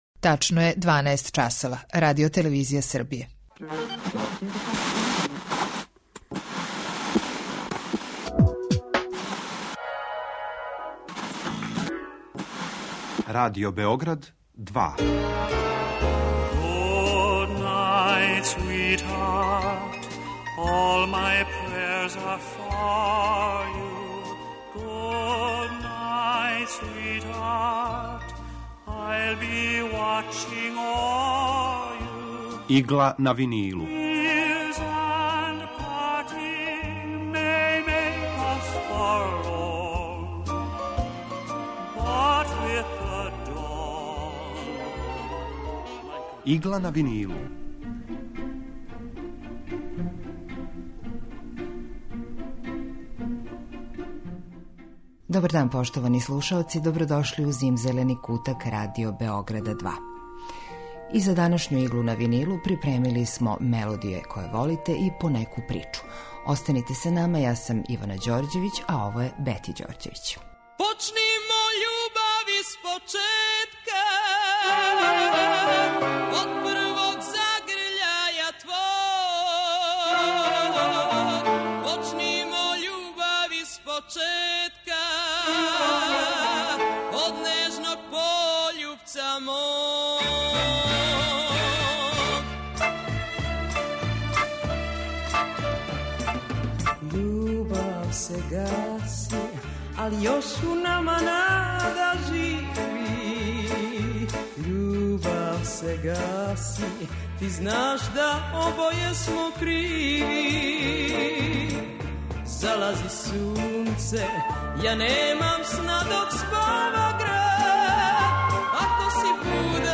Евергрин муѕика
У Игли на винилу представљамо одабране композиције евергрин музике од краја 40-их до краја 70-их година 20. века.